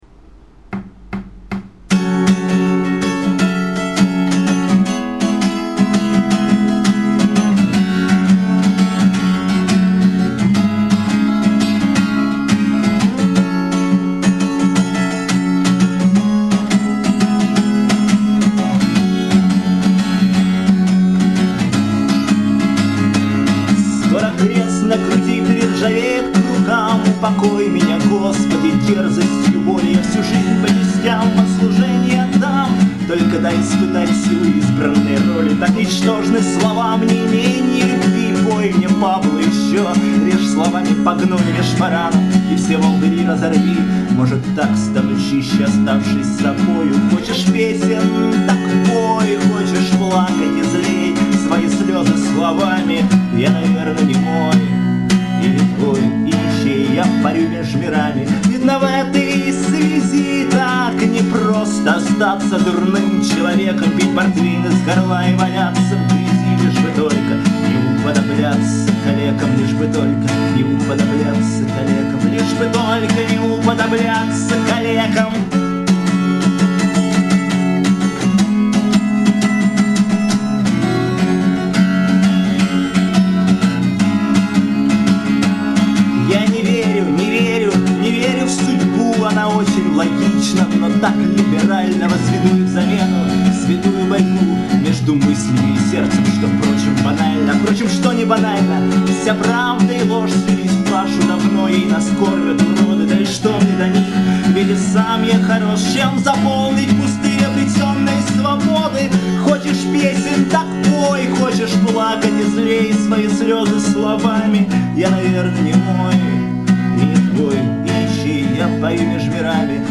А музыка неотступно что-то последнегероевское...